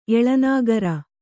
♪ eḷanāgara